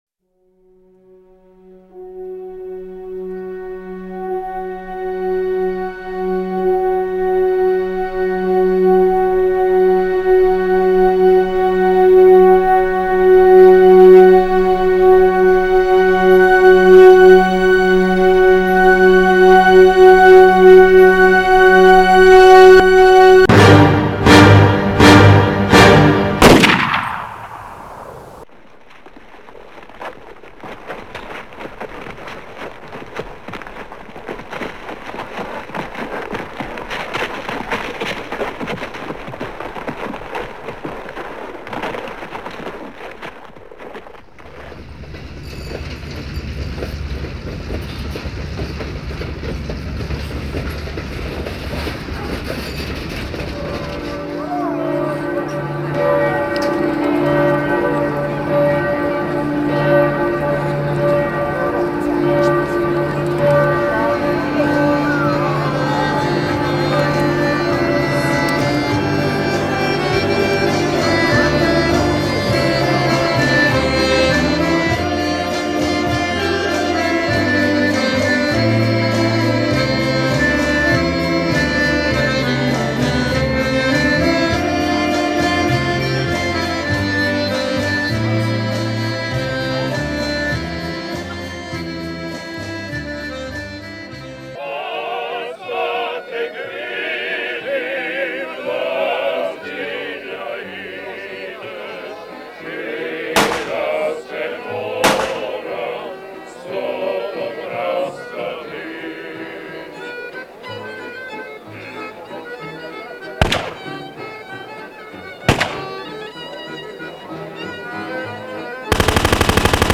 (audio drama, mp3, 54:12 unabridged, 49.6 MB)
WAR AS CRIME is an innovative and gripping work of audio performance drama. It was originally broadcast on CBC Radio One and Two in September, 2002. This is the slightly longer, full version of the work, including the musical prelude.